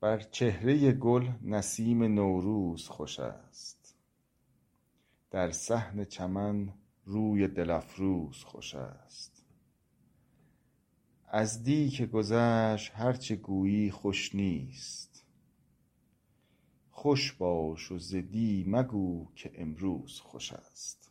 خیام رباعیات رباعی شمارهٔ ۱۹ به خوانش